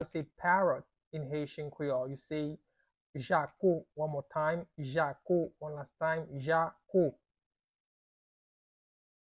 Listen to and watch “Jako” audio pronunciation in Haitian Creole by a native Haitian  in the video below:
How-to-say-Parrot-in-Haitian-Creole-Jako-pronunciation-by-a-Haitian-teacher.mp3